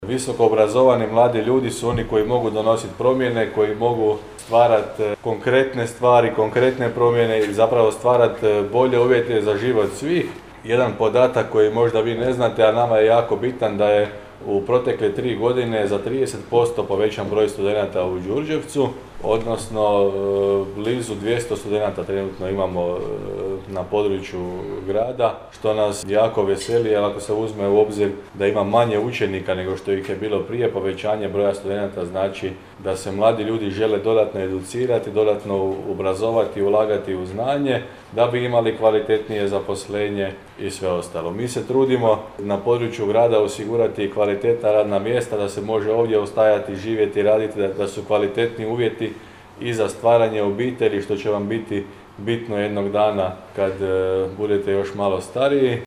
U Hostelu Borik u Đurđevcu održano je svečano potpisivanje ugovora za studentske stipendije Grada Đurđevca, koji već treću godinu osigurava za sve redovne studente s područja Grada i za sve studente koji studiraju na Sveučilištu Sjever u Đurđevcu.